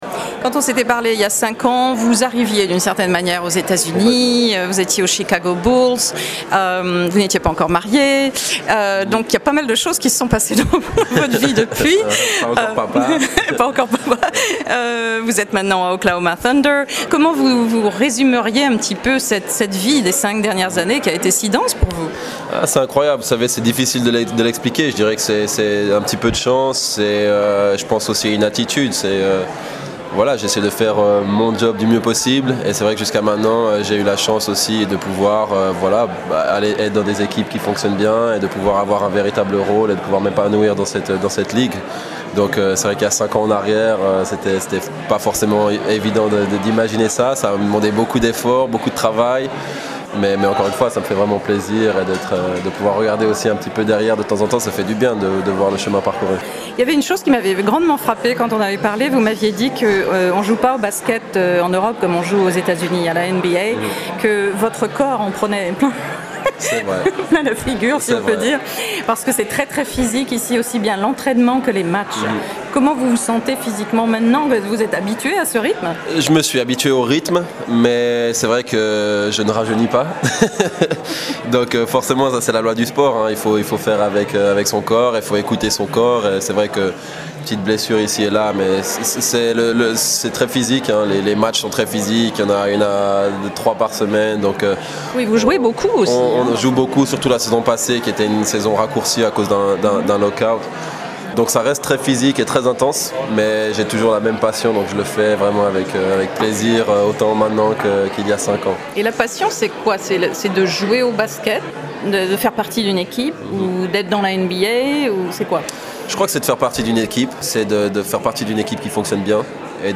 Interview de Thabo Sefolosha